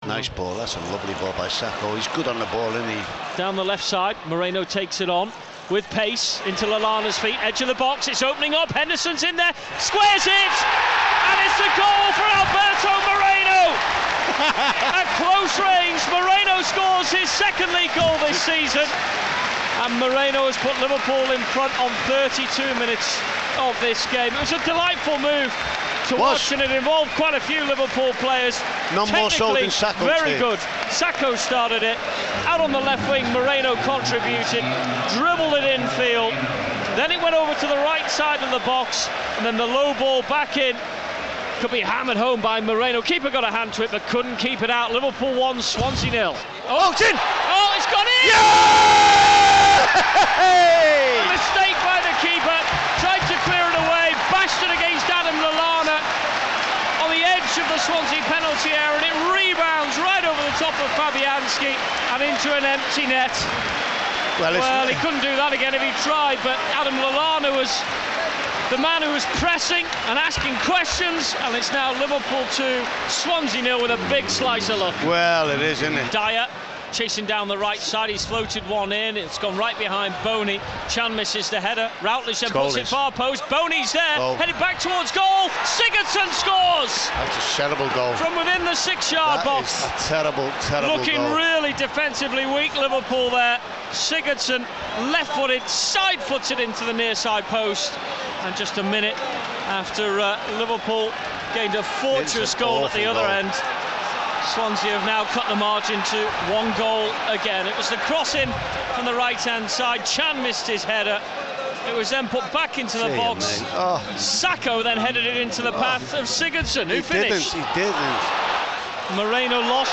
Match highlights from Liverpool's game with Swansea in the Premier League.